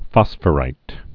(fŏsfə-rīt)